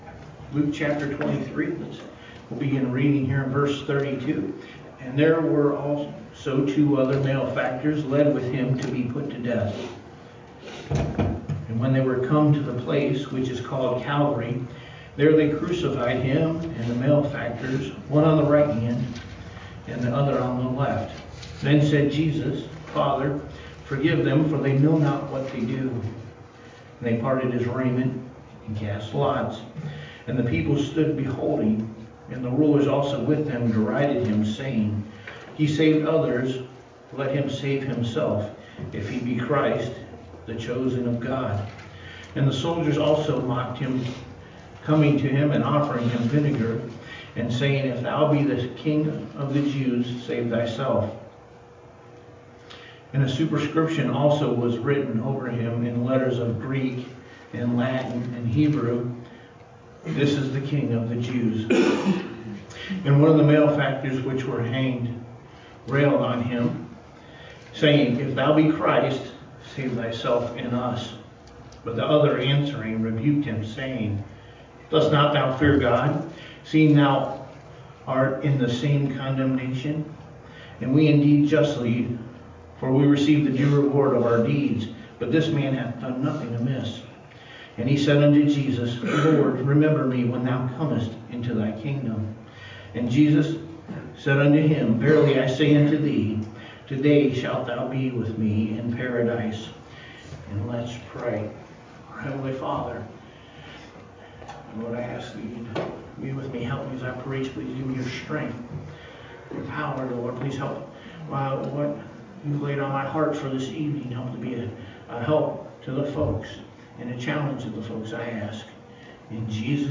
A Condemned Sinners Sermon
A-Condemned-Sinners-Sermon-CD.mp3